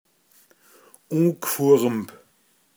pinzgauer mundart
u(n)gfurmb ungebildet, schlecht erzogen